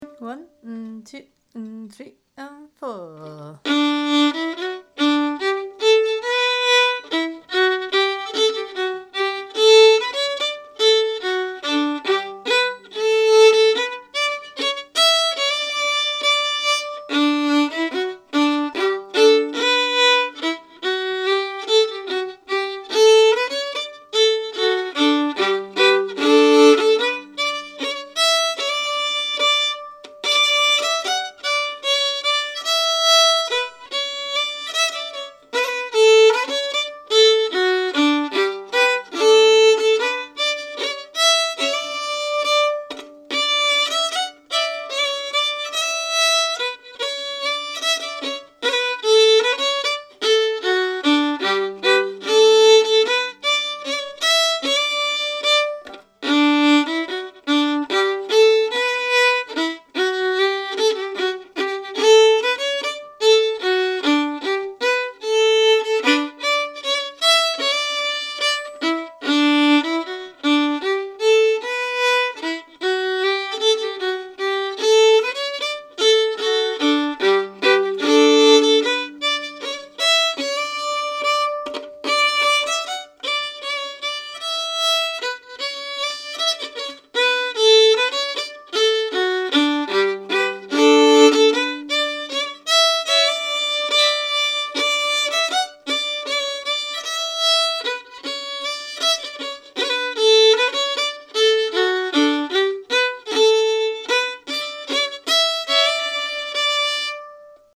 Key: D
Form: Reel
Played slowly for learning
Genre/Style: Morris dance reel
BanburyBillSlow.mp3